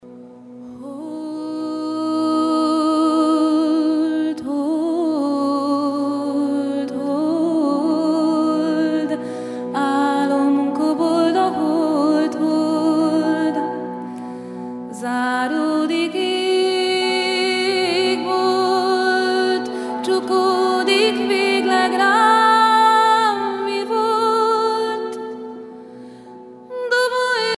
Concert a Brugges Festival, Belgique
Pièce musicale inédite